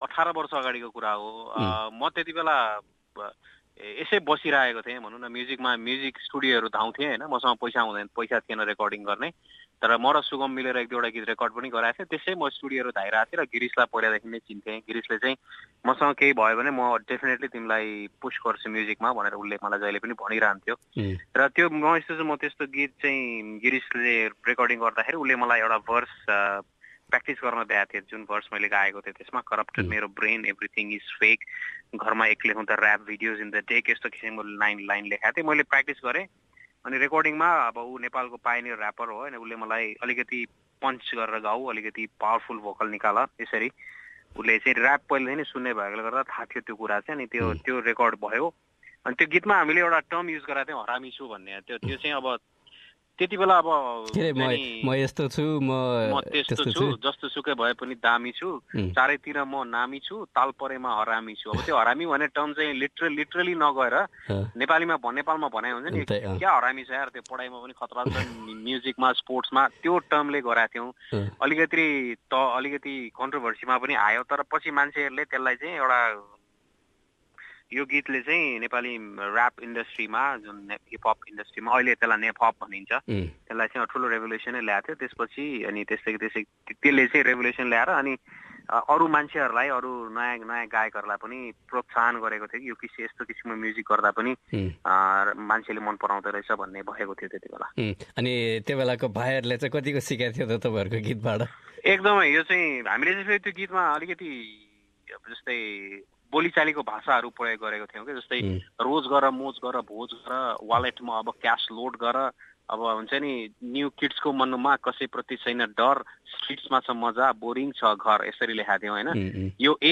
Here he is speaking to SBS Nepali about the song "Ma Yesto Chu" and his future plans.